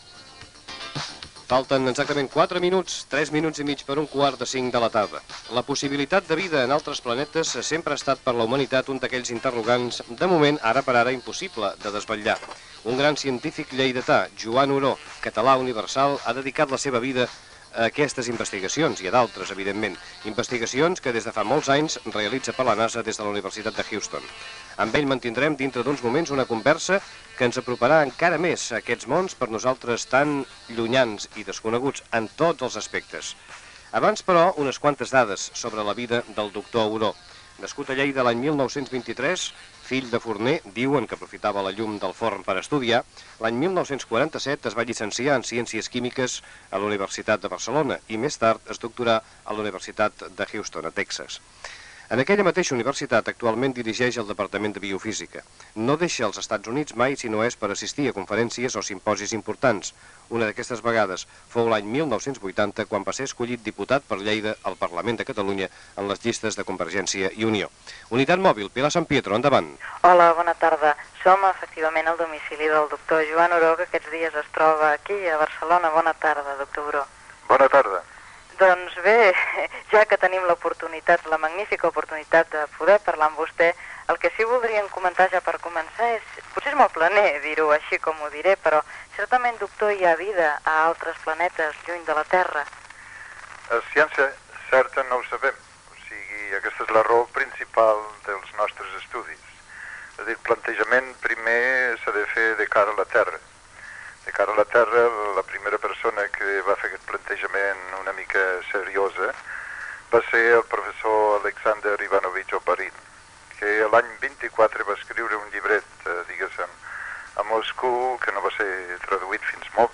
Hora, presentació i entrevista a Joan Oró sobre la possibilitat de vida a l'espai, feta a la seva casa de Barcelona
Info-entreteniment